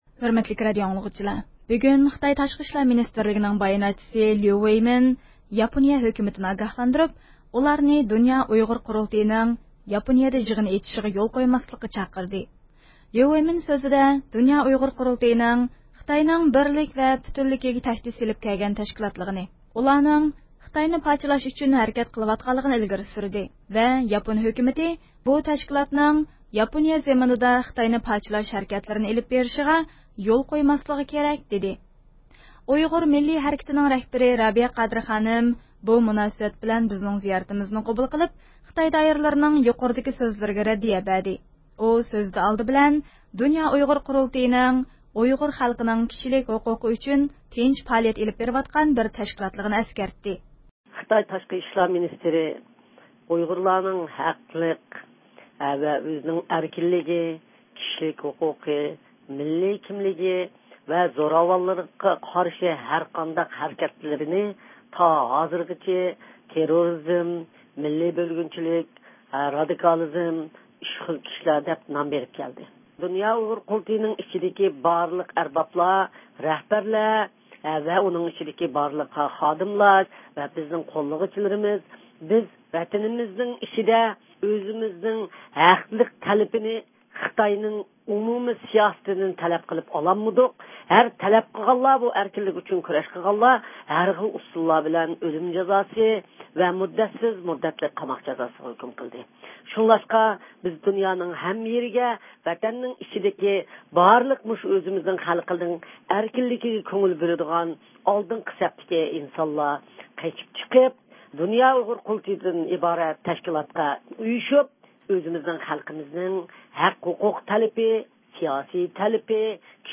ئۇيغۇر مىللىي ھەرىكىتى رەھبىرى رابىيە قادىر خانىم بۇ مۇناسىۋەت بىزنىڭ زىيارىتىمىزنى قوبۇل قىلىپ، خىتاي دائىرىلىرىنىڭ يۇقىرىدىكى سۆزلىرىگە رەددىيە بەردى.